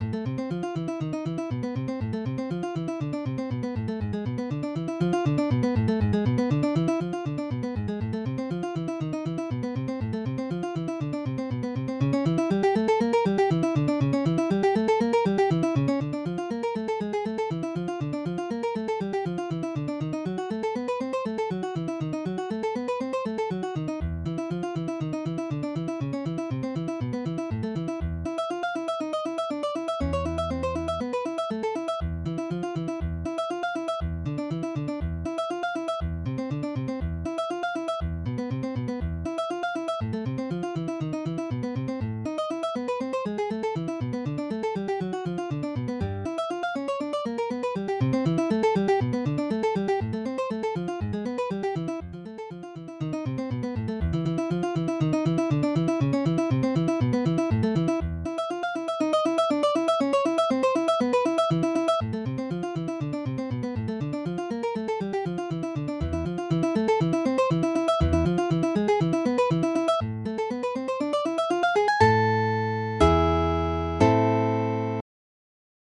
Midi音楽が聴けます 3 200円